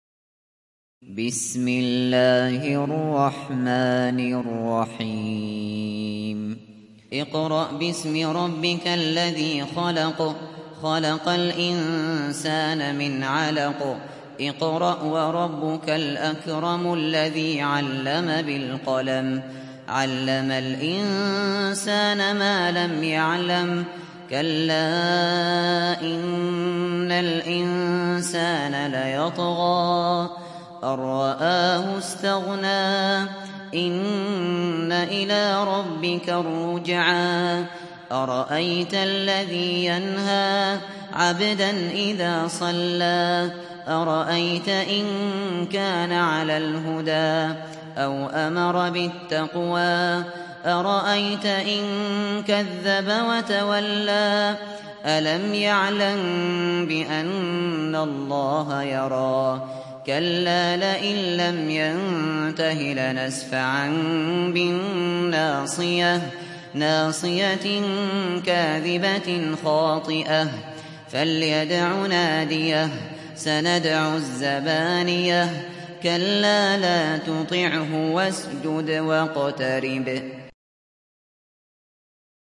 Sourate Al Alaq Télécharger mp3 Abu Bakr Al Shatri Riwayat Hafs an Assim, Téléchargez le Coran et écoutez les liens directs complets mp3
Télécharger Sourate Al Alaq Abu Bakr Al Shatri